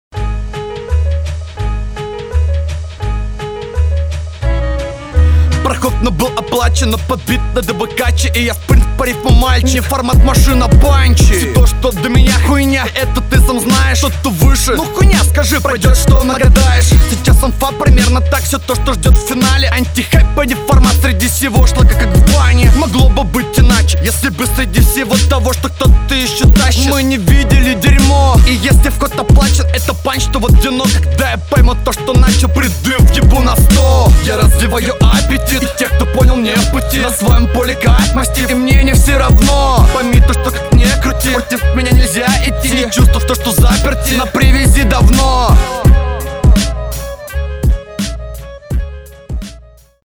Монотонно, скучно.
Флоу рваный, слушать с удовольствием не выходит.